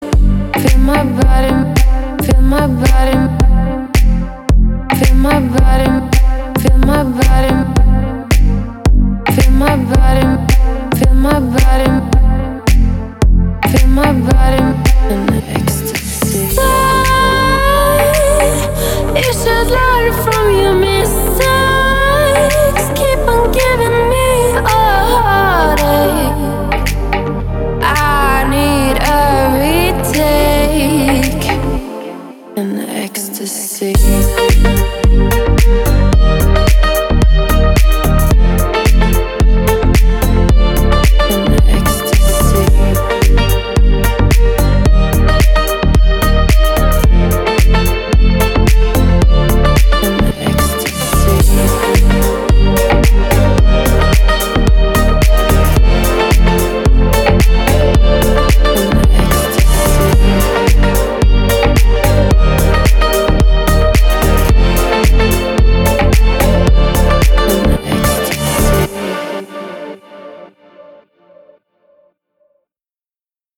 • Качество: 320, Stereo
deep house
мелодичные
чувственные
красивый женский голос